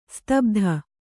♪ stabdha